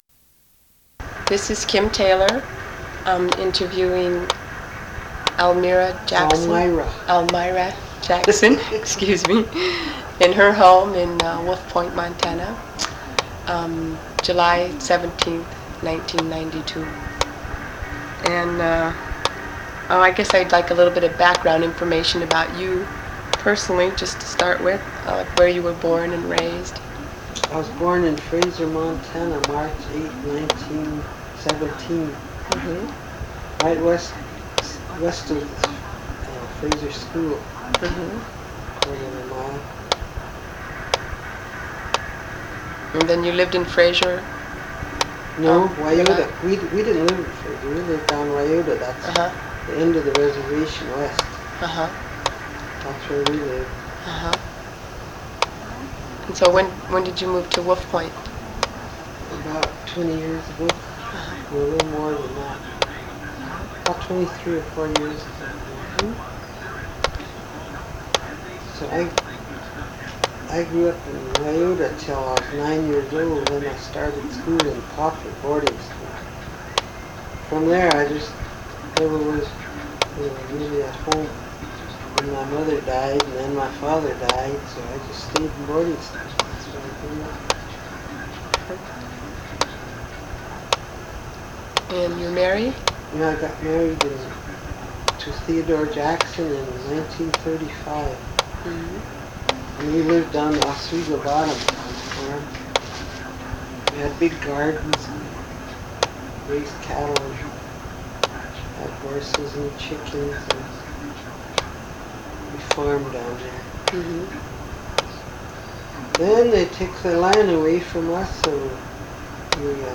Oral History
1 sound cassette (00:27:25 min.) analog